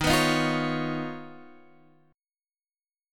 Eb7sus2#5 chord